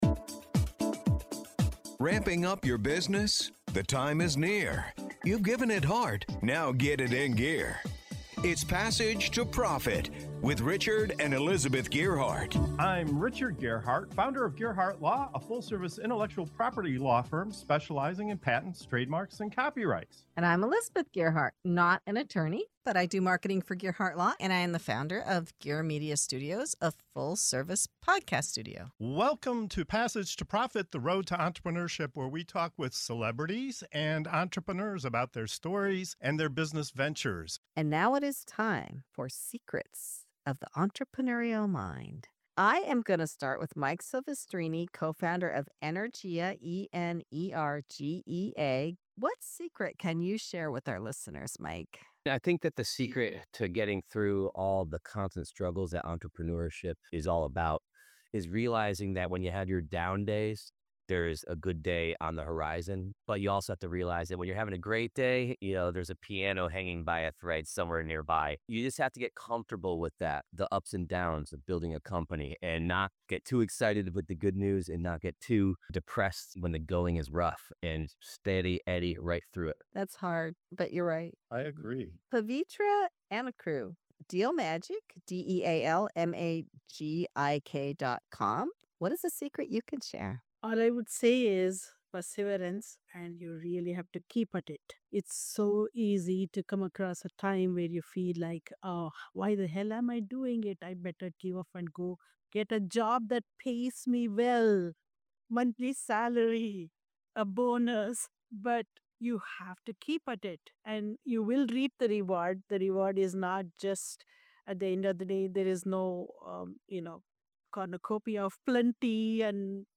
From embracing resilience and perseverance to finding freedom in the entrepreneurial journey, our guests reveal the strategies that keep them moving forward. Plus, discover why planning, perspective, and even AI-driven branding are powerful tools every entrepreneur should be using.